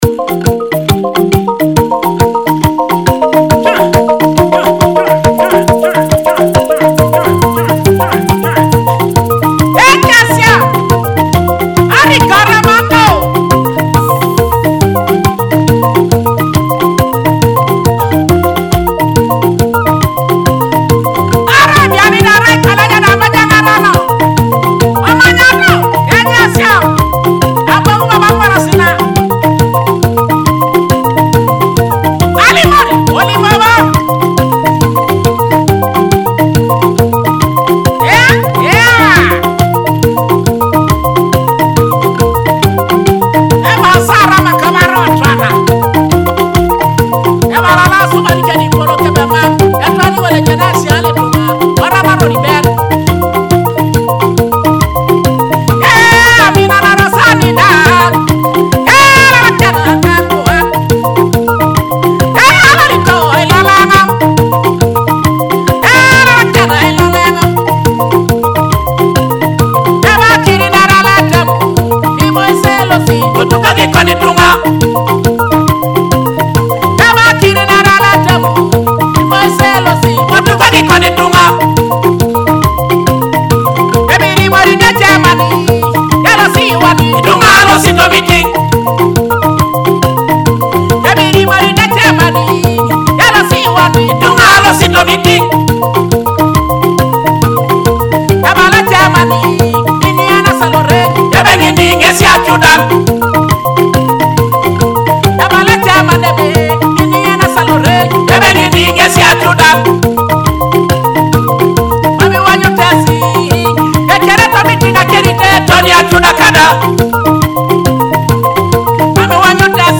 featuring joyful Ateso cultural and traditional rhythms
Akogo (thumb piano)